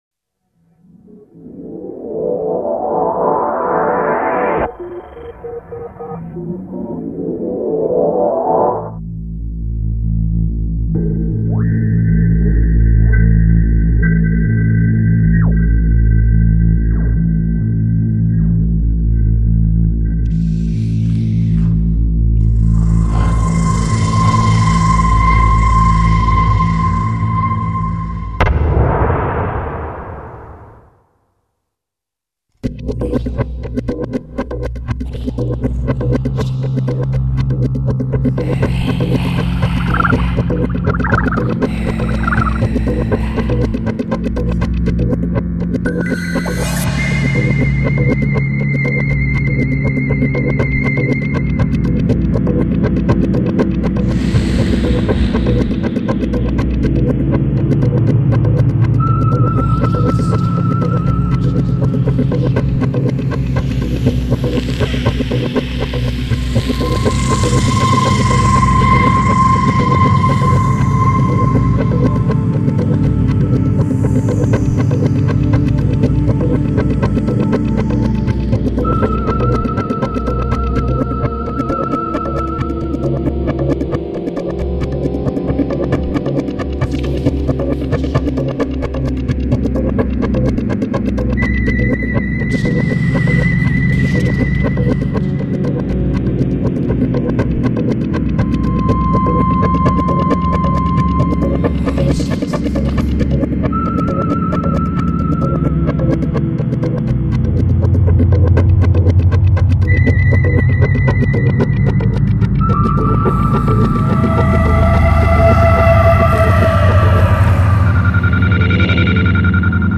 Hi Fi